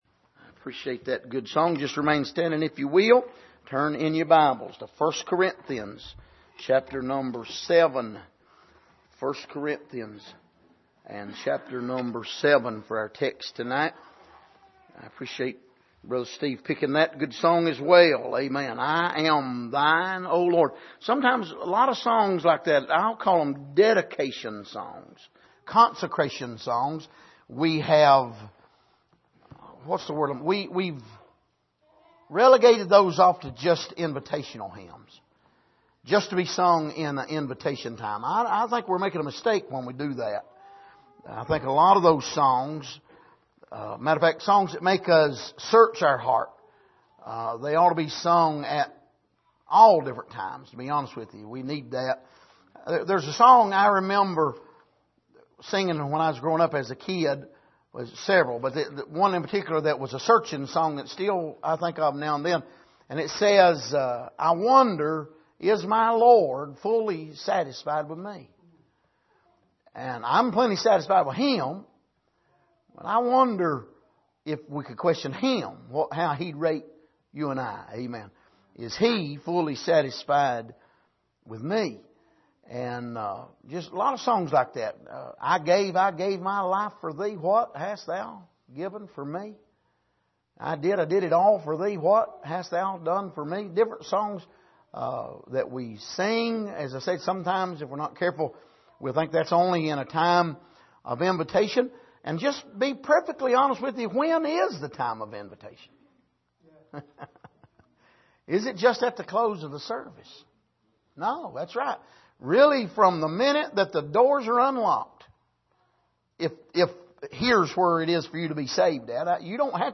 Miscellaneous Passage: 1 Corinthians 7:29-31 Service: Sunday Evening